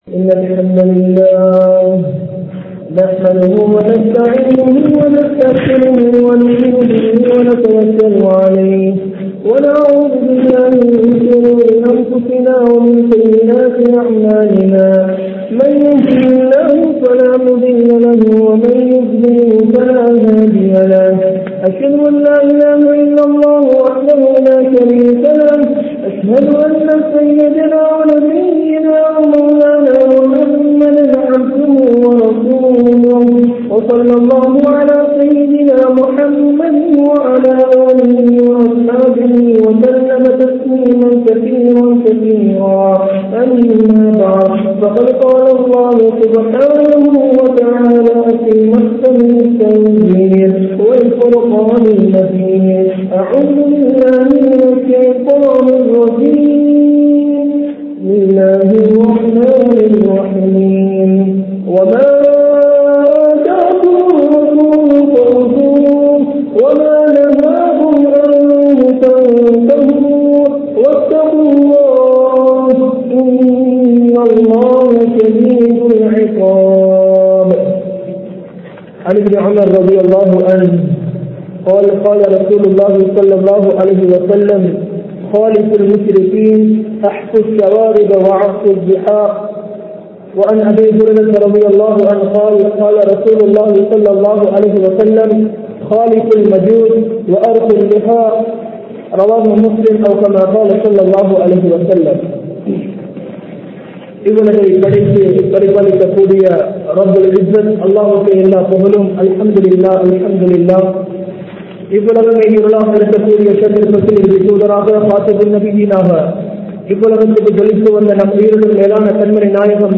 Islaththil Dhaadien Mukkiyaththuvam (இஸ்லாத்தில் தாடியின் முக்கியத்துவம்) | Audio Bayans | All Ceylon Muslim Youth Community | Addalaichenai
Majma Ul Khairah Jumua Masjith (Nimal Road)